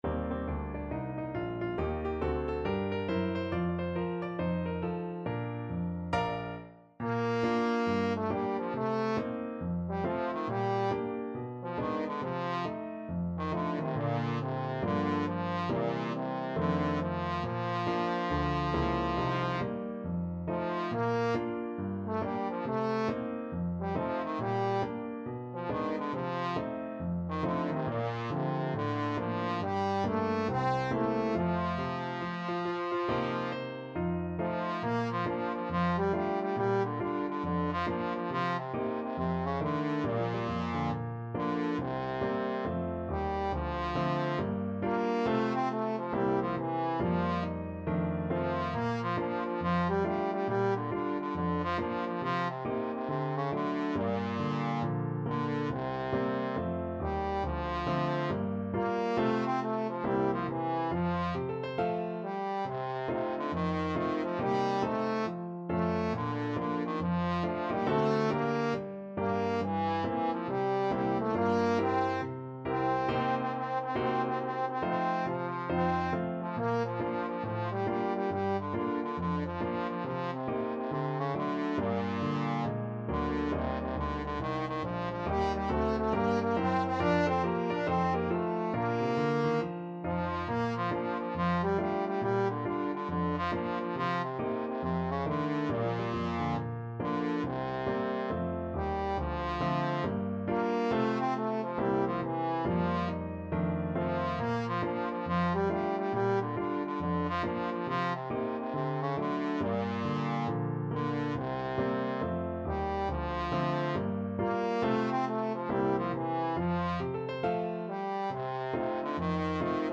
4/4 (View more 4/4 Music)
With a swing =c.69
Pop (View more Pop Trombone Music)